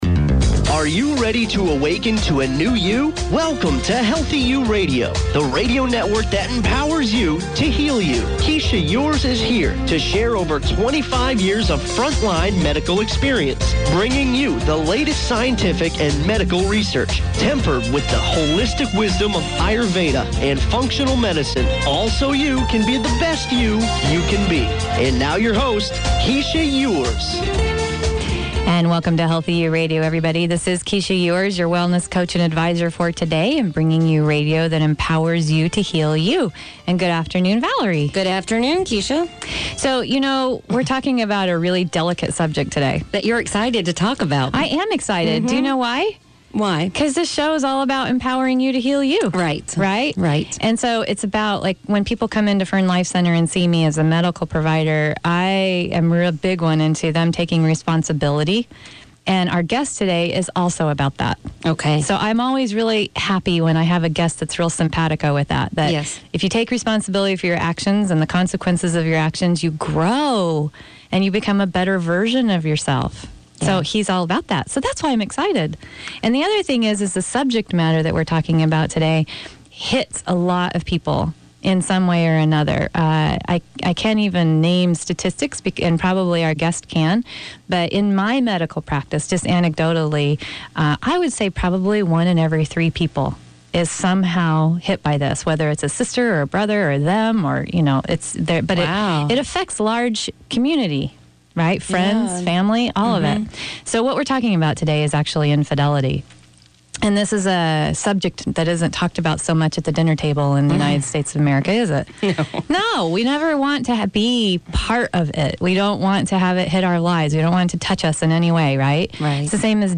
Recovering from infidelity Interview